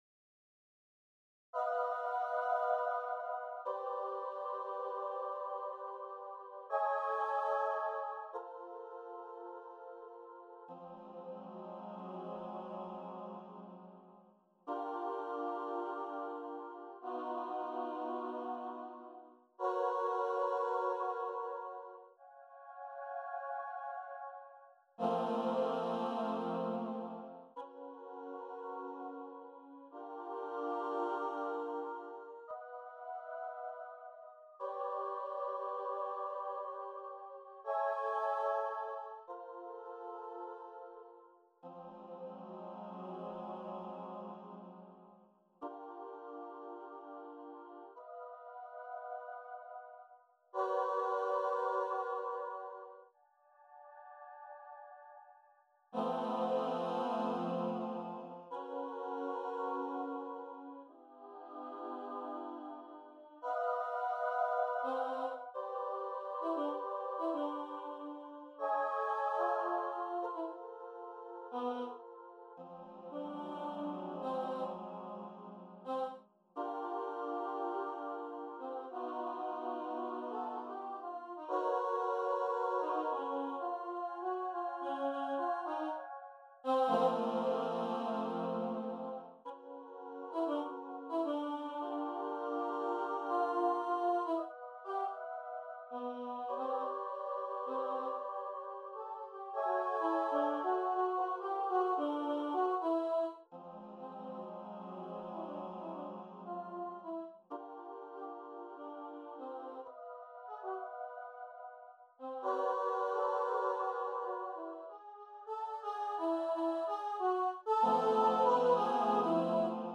Instrumentation ： 12 Voices or any 12 performers
12th concert at JT Art Hall Affinis